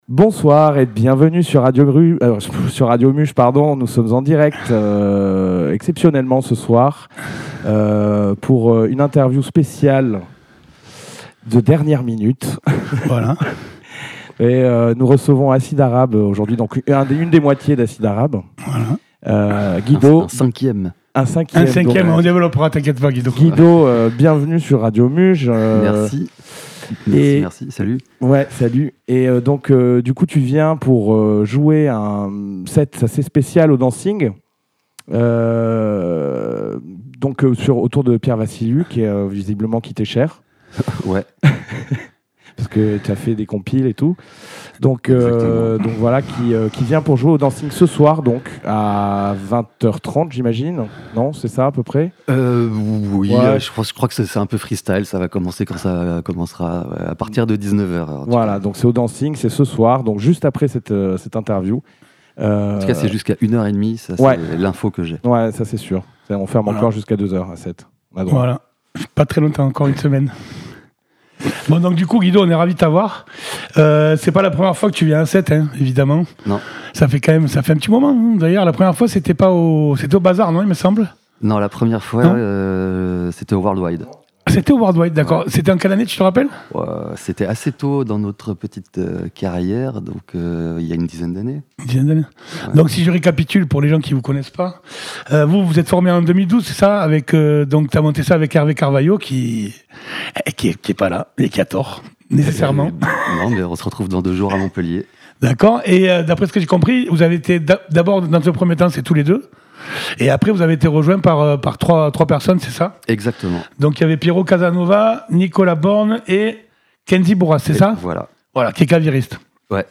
ITW enregistrée & diffusée en Live @ Radio Muge le 26/09/24.